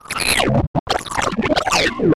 AFX_DROIDTALK_6_DFMG.WAV
Droid Talk 6